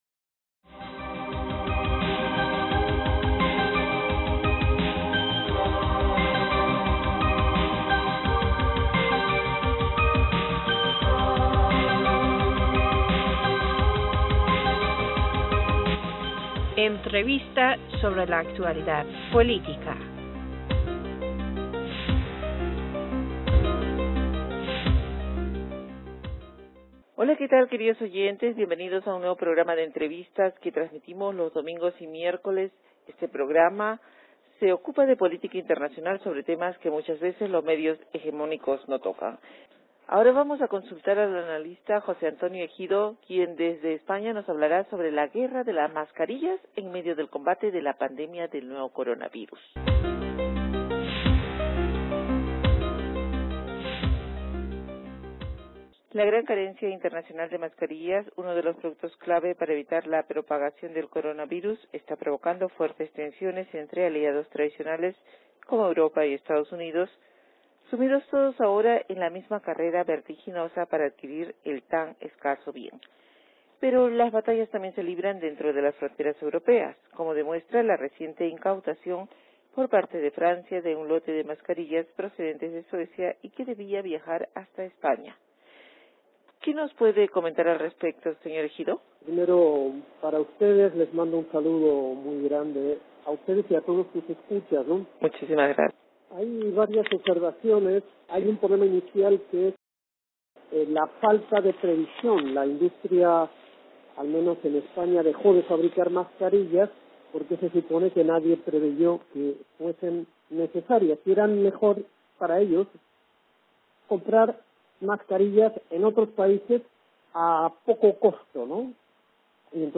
Entrevistador (E)